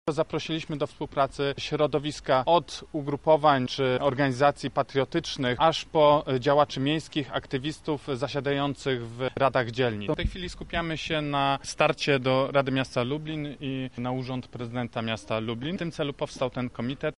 Mówi Jakub Kulesza z Kukiz 15.